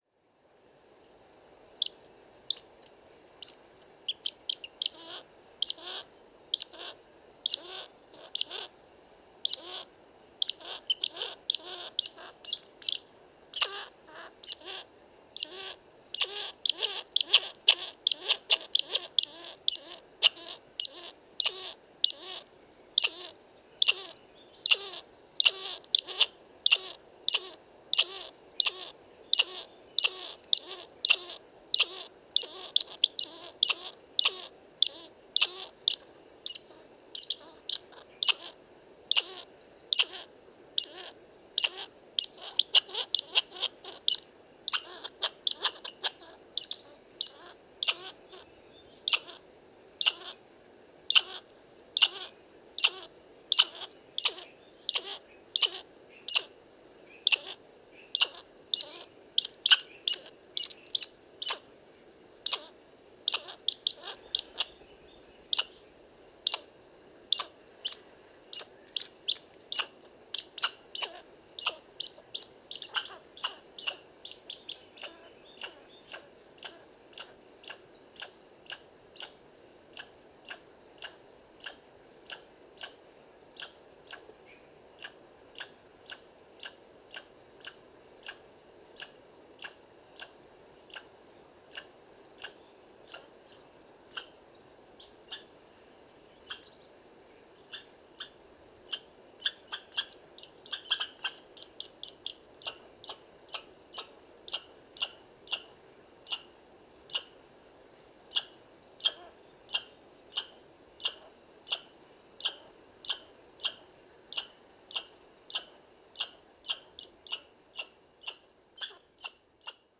Écureuil roux – Tamiasciurus hudsonicus
Parc National du Mont-Tremblant QC, 46°18’48.9″N 74°33’37.9″W. 21 mars 2018. 12h30.
Espèces : Écureuil roux, Tarin des pins, Sittelle à poitrine rousse, Geai bleu, Pic mineur.